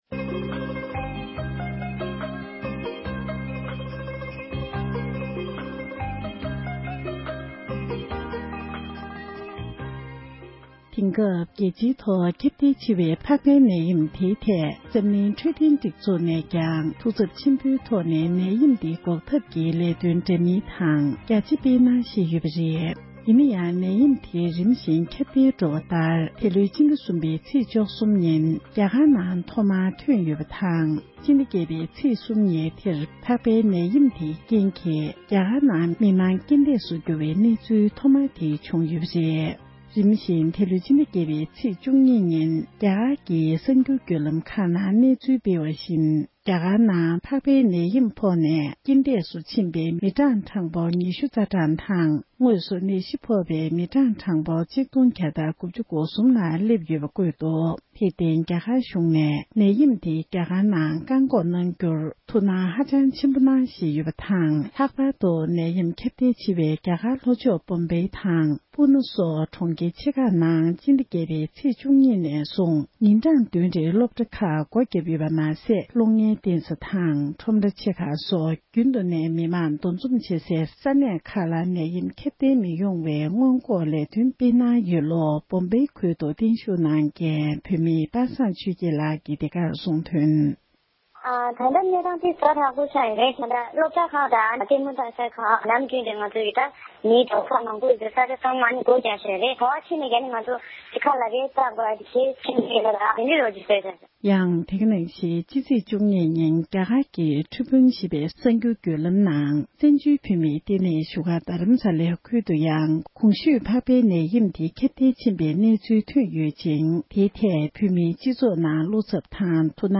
འབྲེལ་ཡོད་མི་སྣར་བཀའ་འདྲི་ཞུས་པ་ཞིག